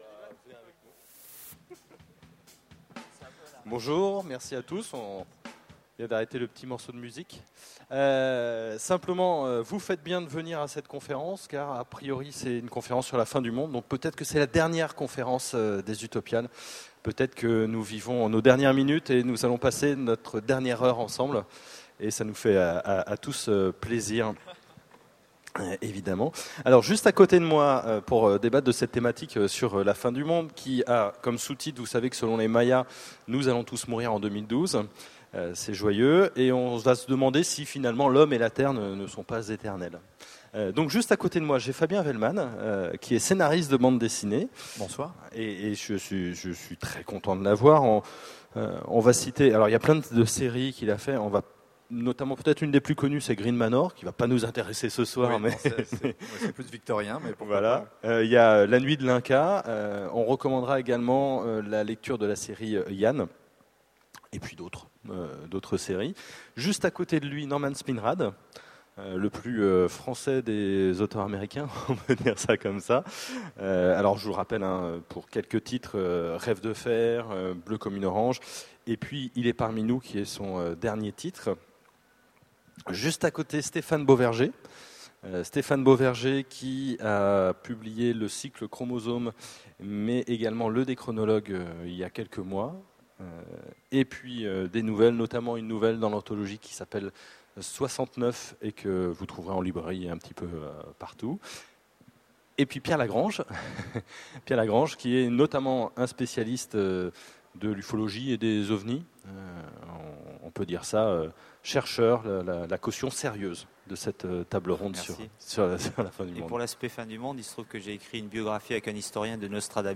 Voici l'enregistrement de la conférence sur "La fin du Monde" aux Utopiales 2009.